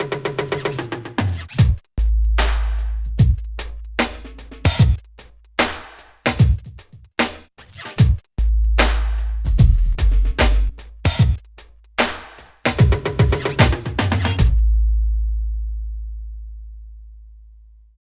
108 LOOP  -L.wav